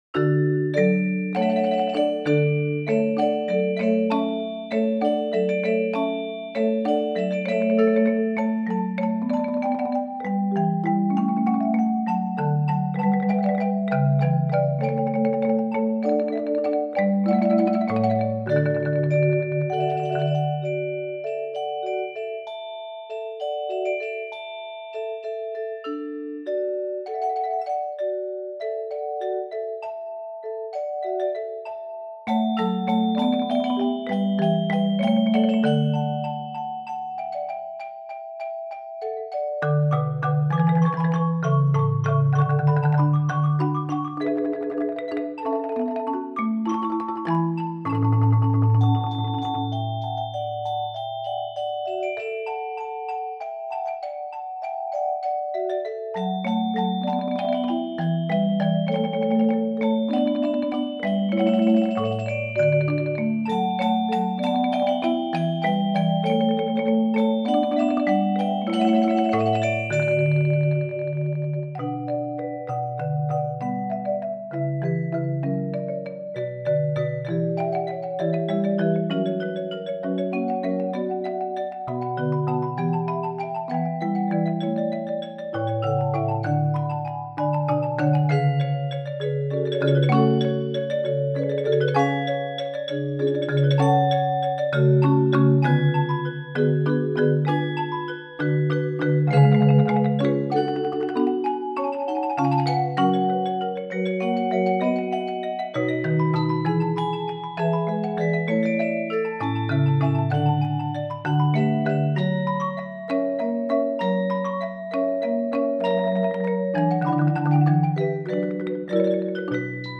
Voicing: Mallet Octet